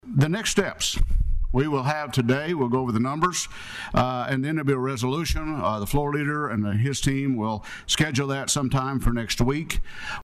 CLICK HERE to listen to commentary from Appropriations Chair Roger Thompson.